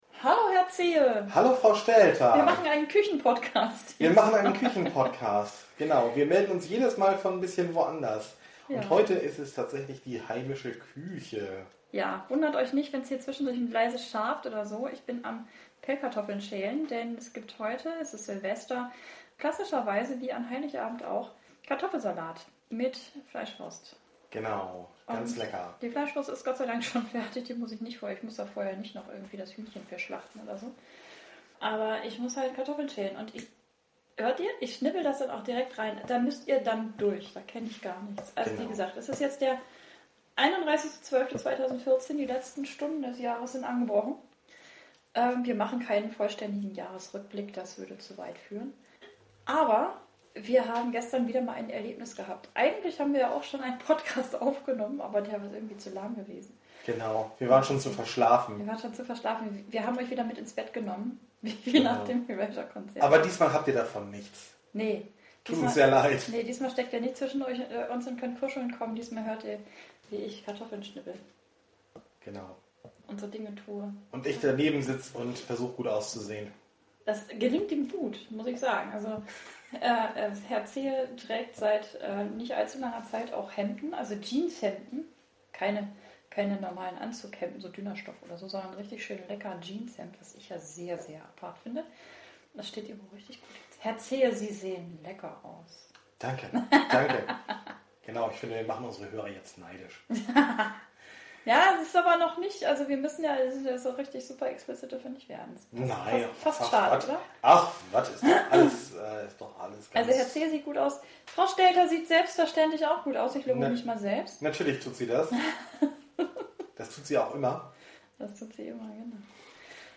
Es gab zwei lockere Folgen, dann zwei nicht ganz so lockere Folgen unseres Podcasts, also entschieden wir uns, so zum Jahresausklang, eine Sabbelfolge aus unserer Küche aufzunehmen. Ganz ohne bedrückende oder ernste Themen, sondern wieder mit viel Spaß und Gelächter.